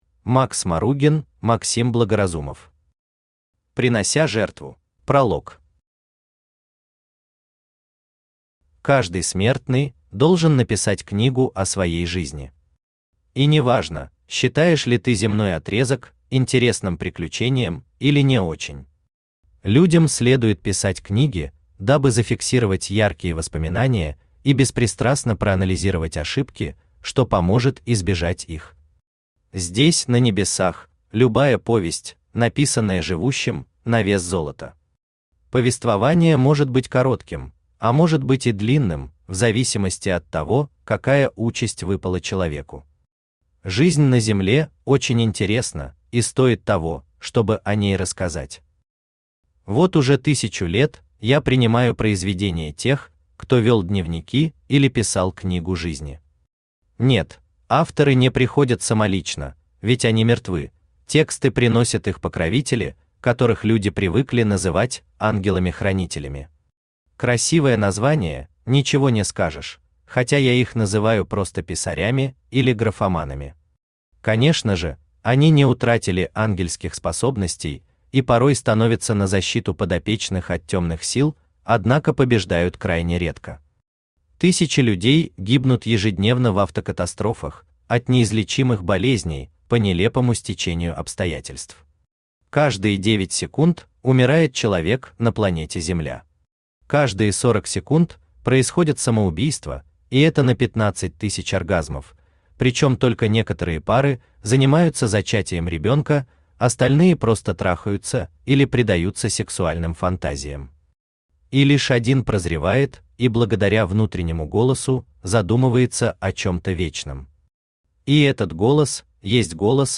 Aудиокнига Принося жертву Автор Макс Маругин Читает аудиокнигу Авточтец ЛитРес.